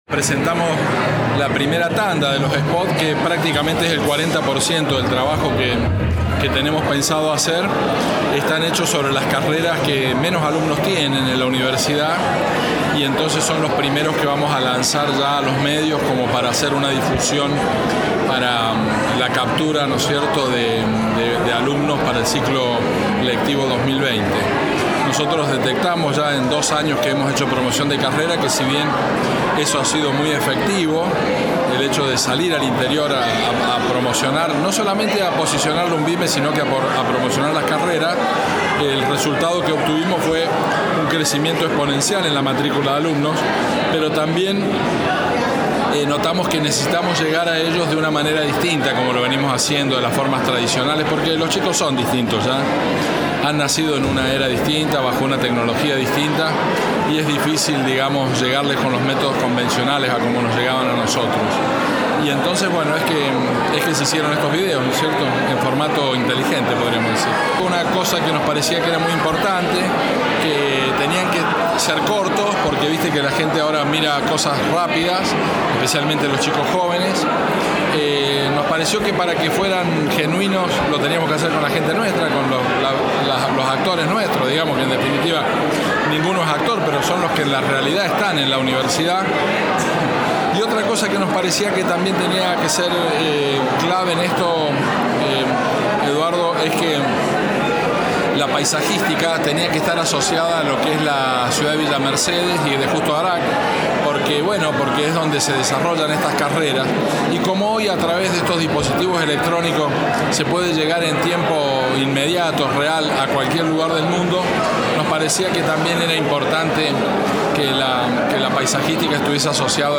Rector normalizador Dr. David Rivarola
RECTOR-D.-RIVAROLA-AVANT-PREMIER.mp3